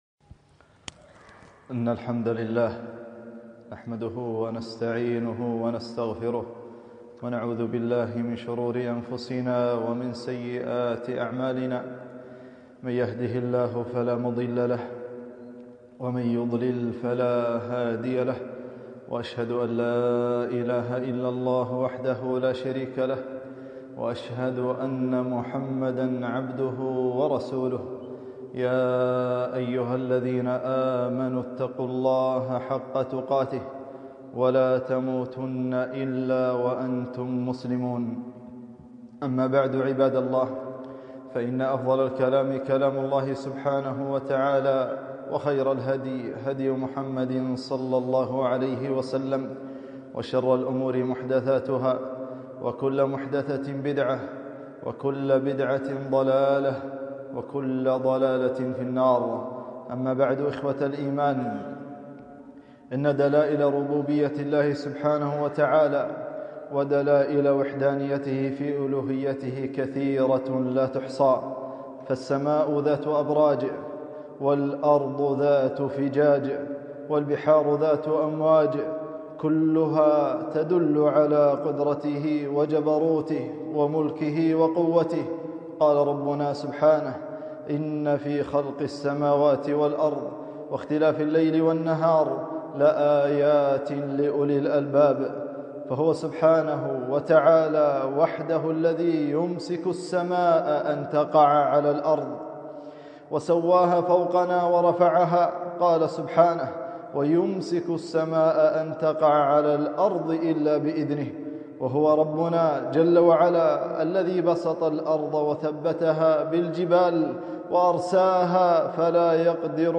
خطبة - عبر وعظات من الزلازل